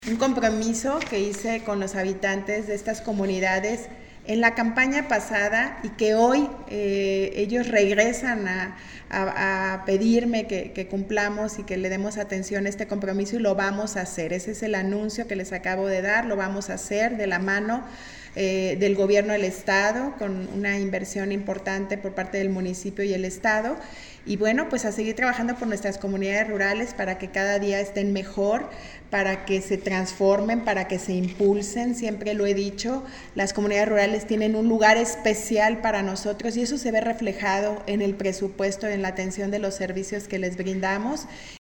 Lorena Alfaro García, presidenta municipal